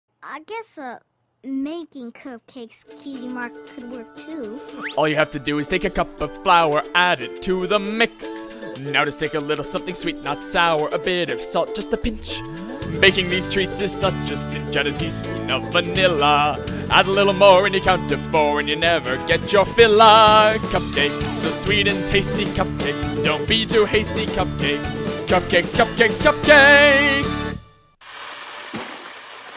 A couple extra seconds left in for the silly sound effect.